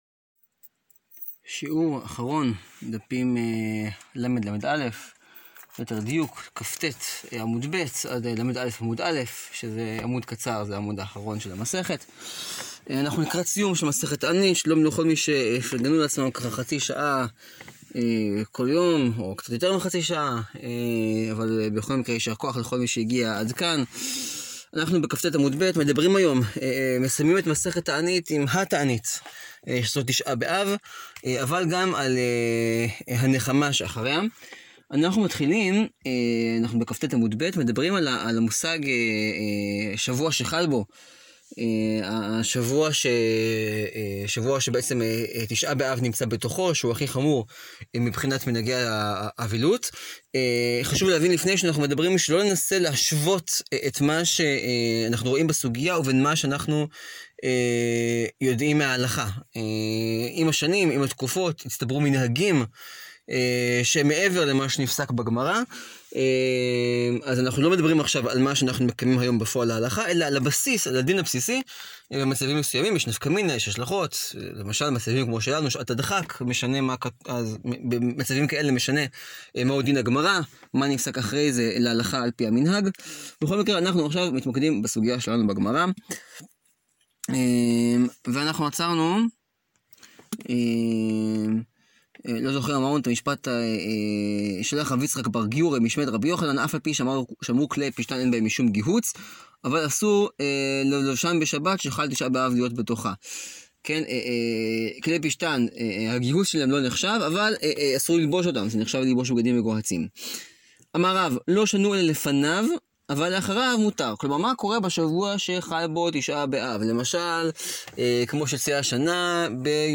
שיעור 15 להאזנה: מסכת תענית, דפים ל-לא.